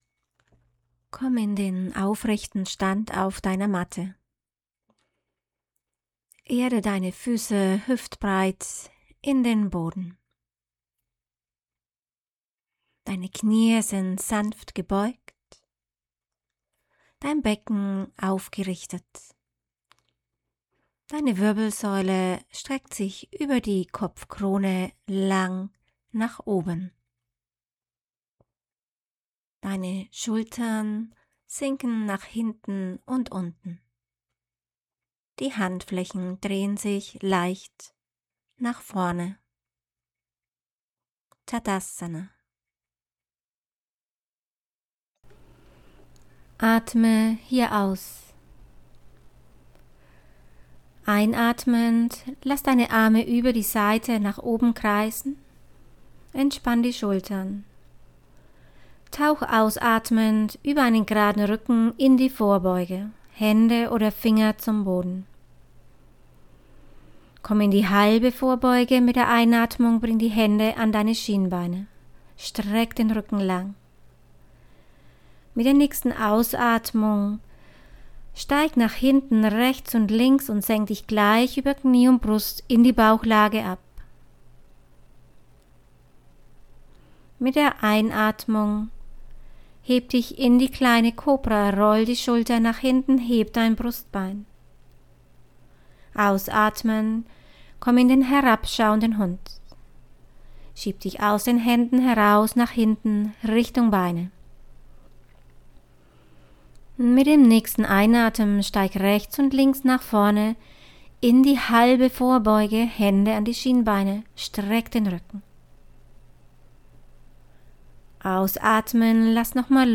Audio-Yoga
Und wenn man die Stimme kennt und nach ein paar Stunden weiß, wie die Yogalehrerin anleitet, ist das wunderbar.